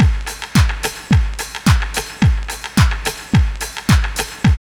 09.1 LOOP1.wav